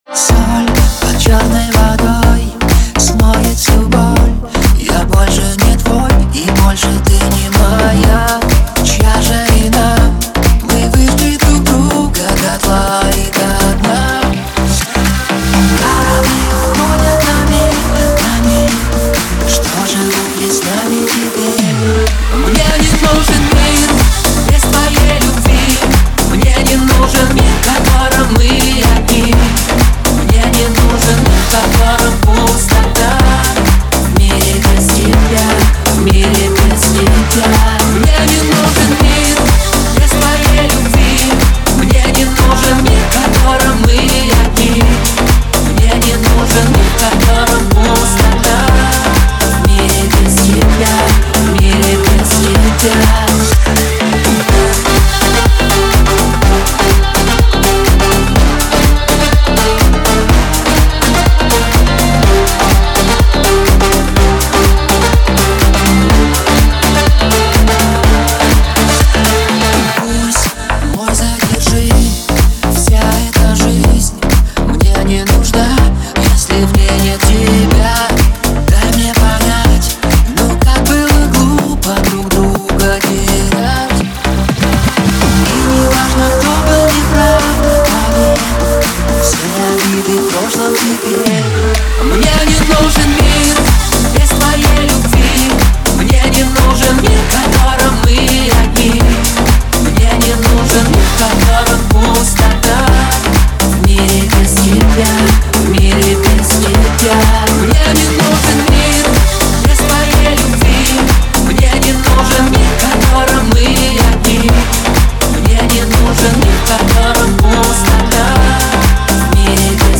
романтичная поп-песня
оформленная в современном радиоформате.